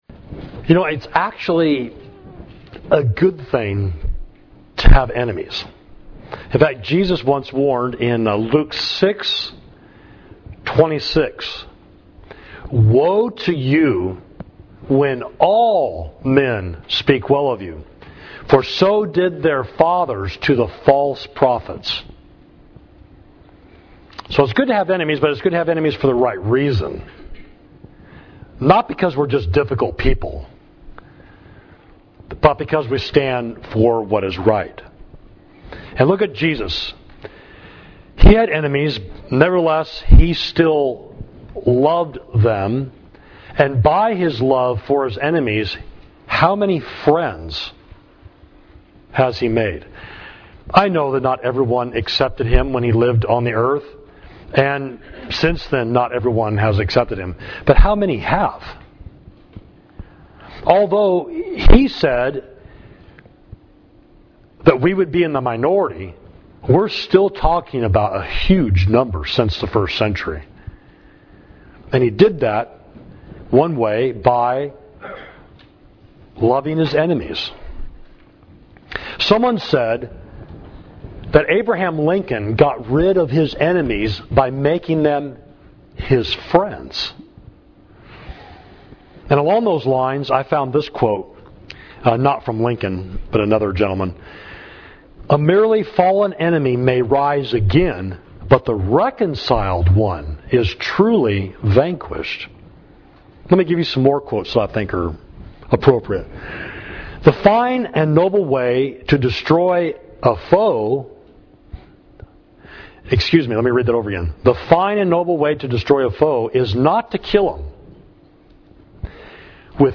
Sermon: Quench the Thirst of Your Enemy, Romans 12.19–21